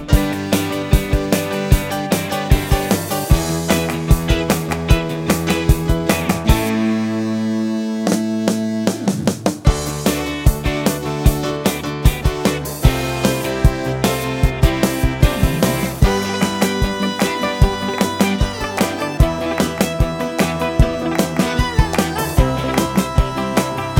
no bass Pop (1970s) 3:59 Buy £1.50